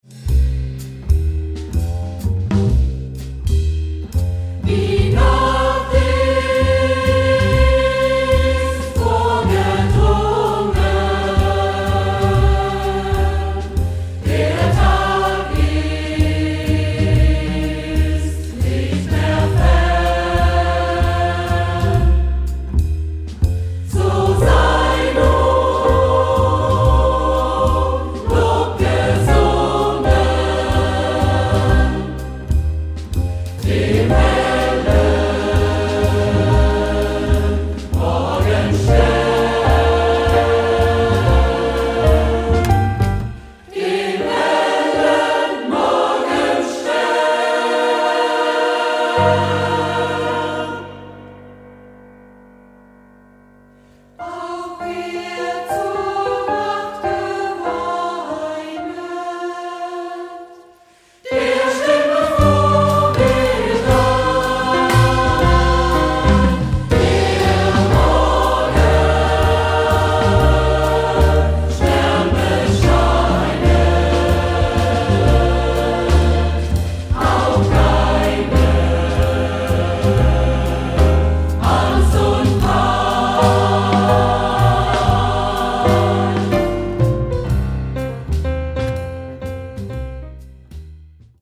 wie reizvoll sich auch ein Saxophon einbringt.
Besetzung: S.A.T.B., Klavier, Rhythmusgruppe ad lib.
Hörprobe >>>